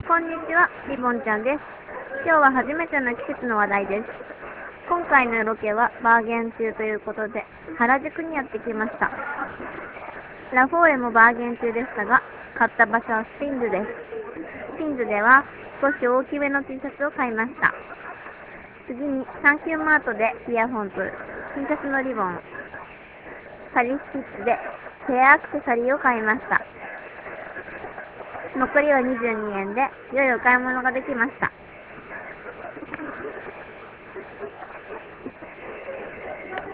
リポート等の音声はスタジオ録音ではなく現地録りになりますので他の人の声などの雑音が入っていたりしますがご了承願います。